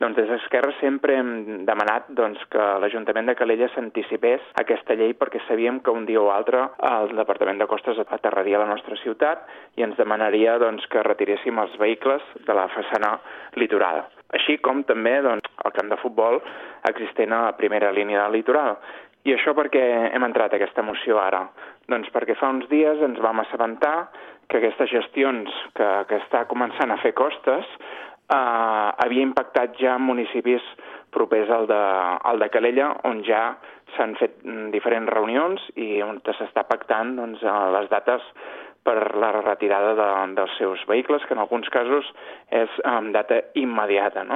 Xavier Ponsdomènech és el regidor portaveu d’ERC.